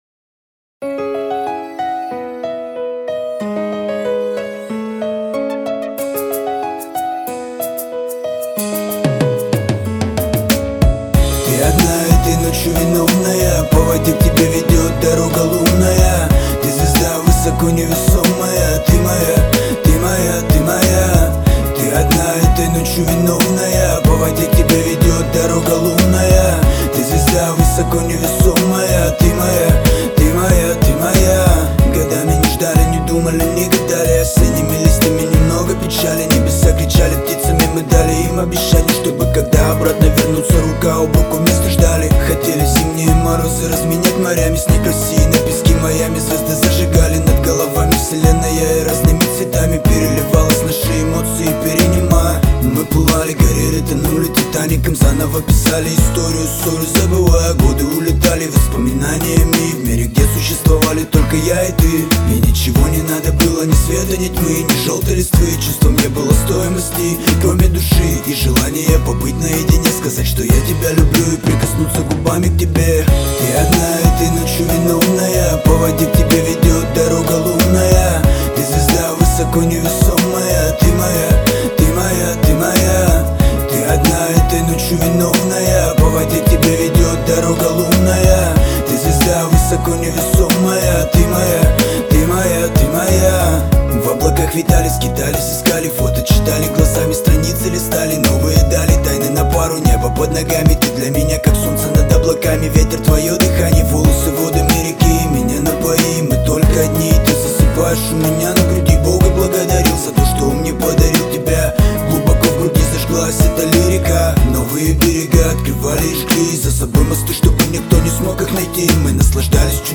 Категория: Русский реп, хип-хоп